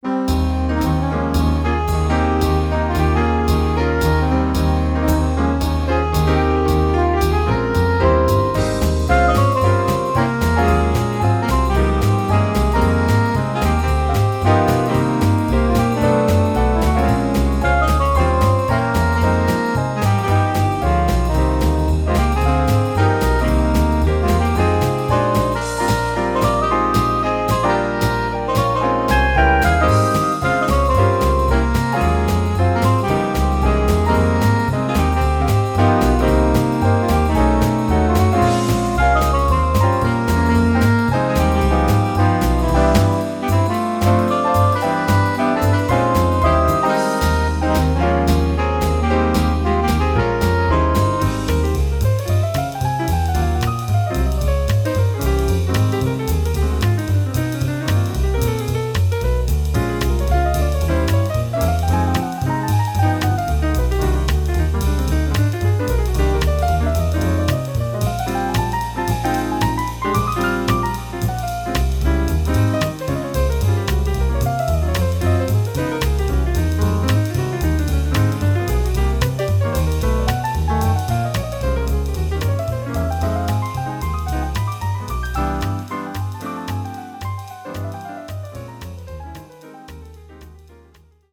Here are demo recordings I did at home of ten original pieces for jazz quintet. These are all short clips (1-2 minutes); the head with a chorus or two of piano solo.